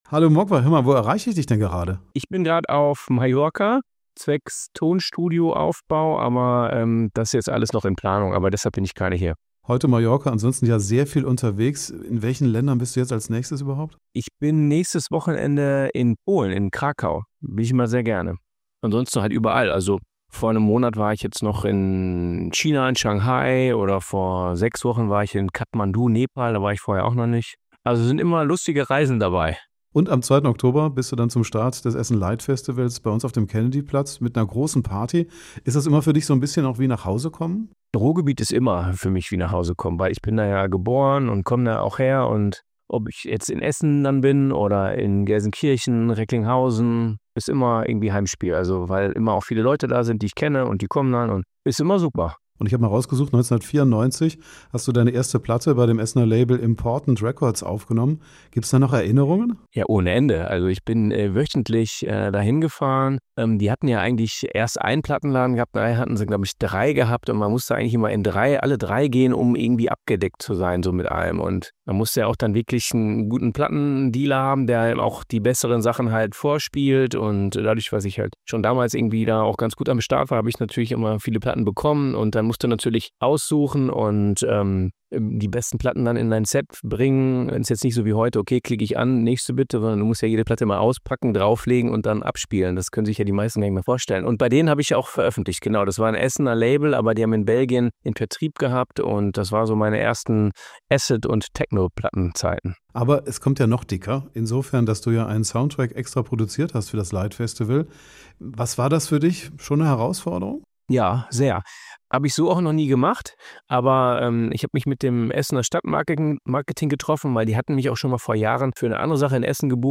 Vor seinem Auftritt in Essen sprechen wir mit Moguai über seinen Einsatz beim Festival.